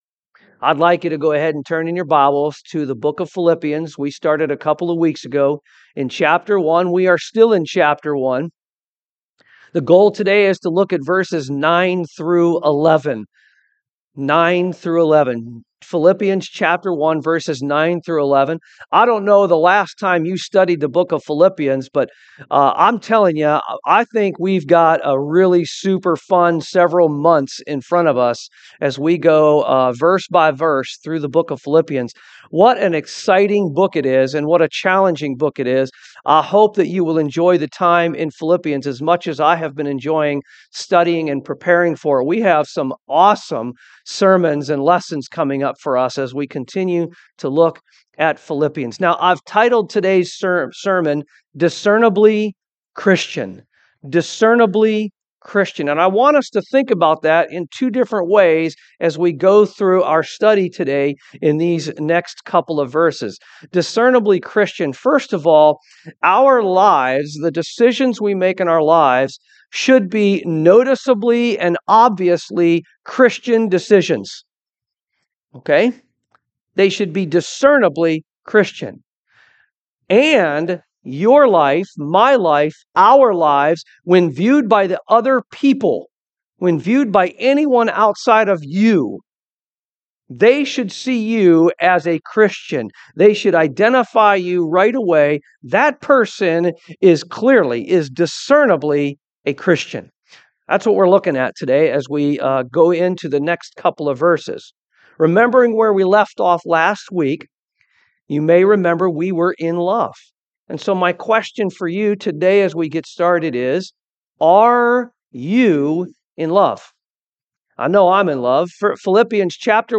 Philippians 1:9-11 Service Type: AM That we claim to be in love with God we ought then to be discernibly Christian.